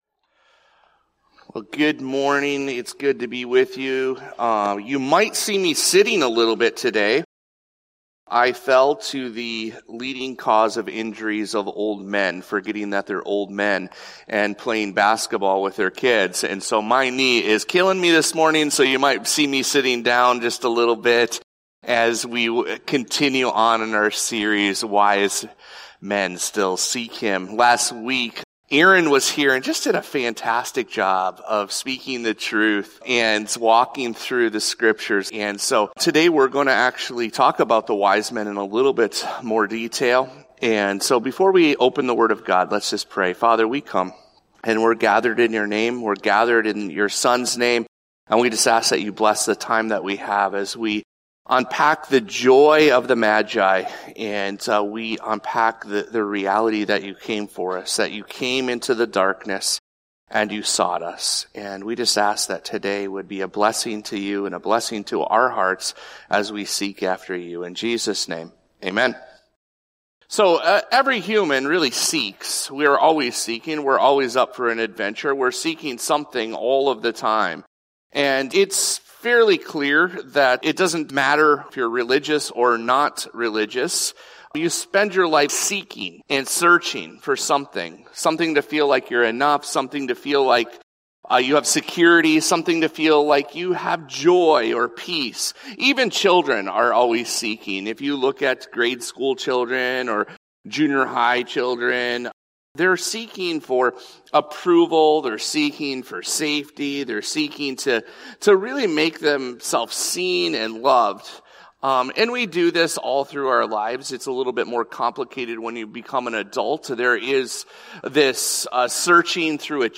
This episode of the Evangel Houghton podcast is a Sunday message from Evangel Community Church, Houghton, Michigan, December 21, 2025.